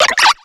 Cri de Moustillon dans Pokémon X et Y.